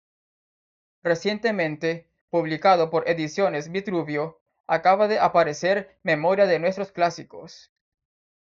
a‧pa‧re‧cer
Uitgespreek as (IPA)
/apaɾeˈθeɾ/